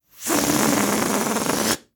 Salida del aire de un globo rapido